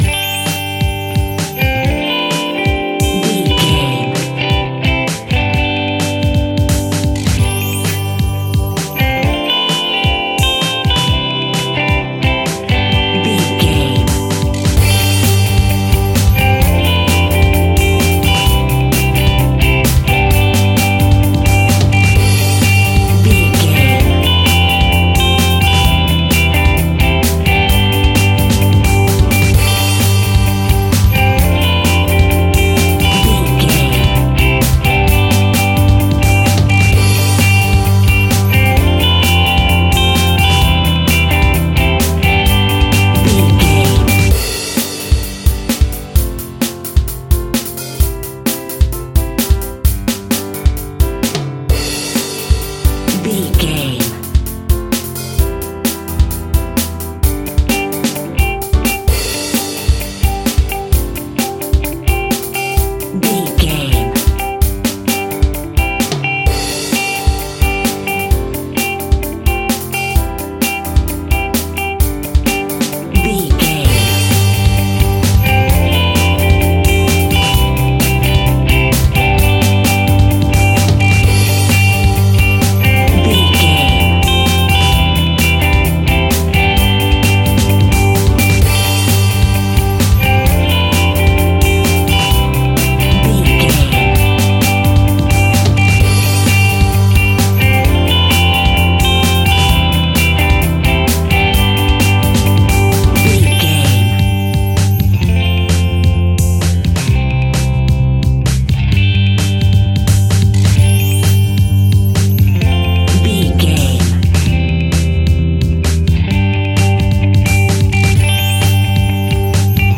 Ionian/Major
pop rock
indie pop
fun
energetic
uplifting
drums
bass guitar
electric guitar
synthesizers